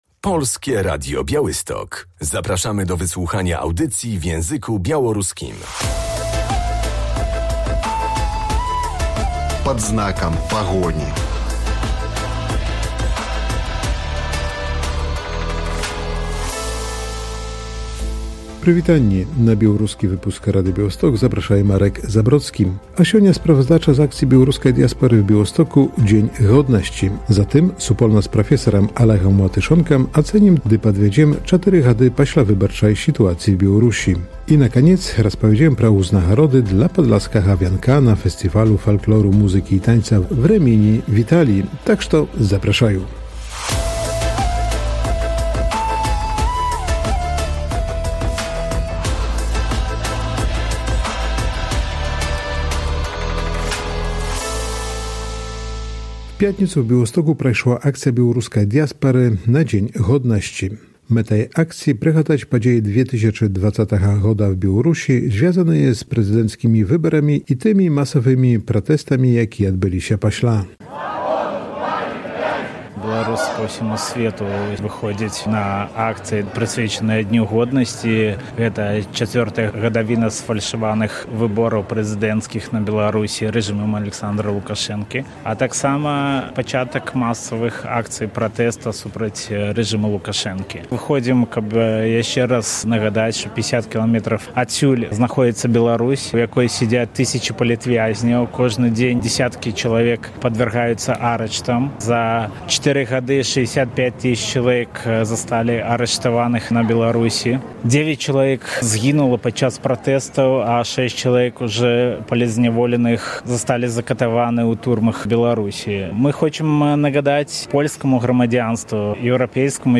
Wiec upamiętniający czwartą rocznicę represji w Białorusi 11.08.2024